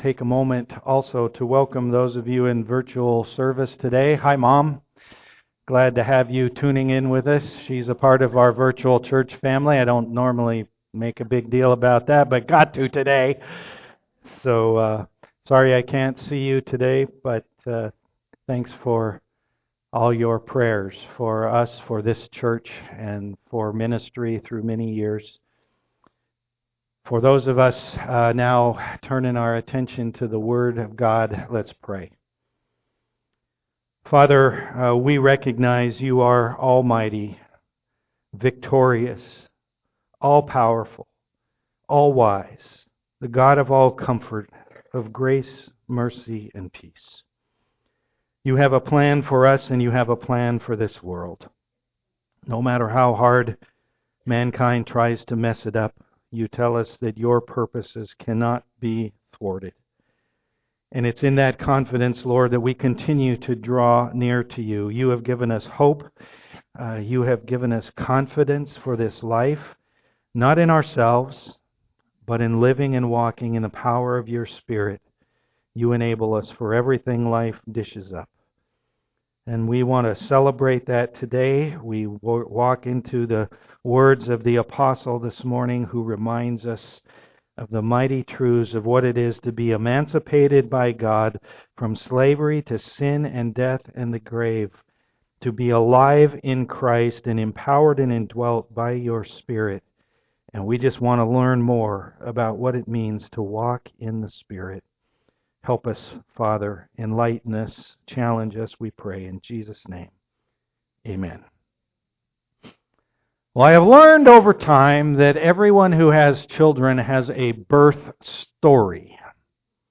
Romans 8:18-25 Service Type: am worship Life is full of groaning